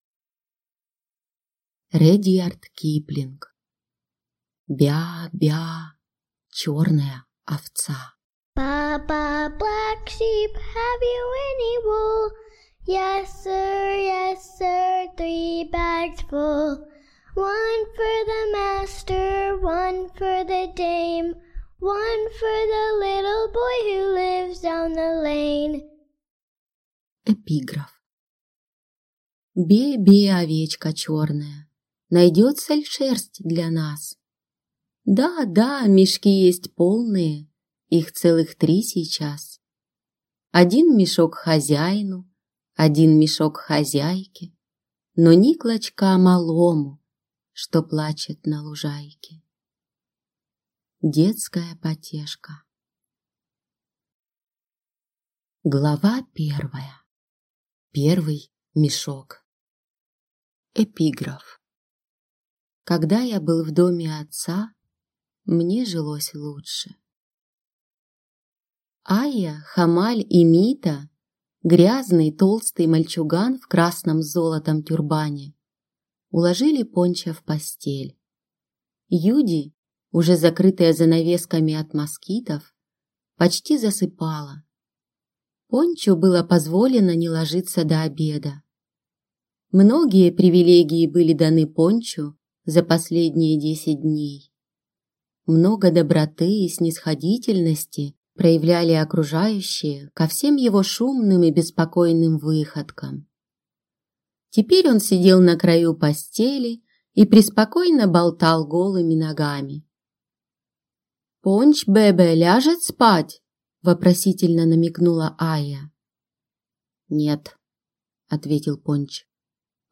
Аудиокнига Бя-а, бя-а, Черная овца | Библиотека аудиокниг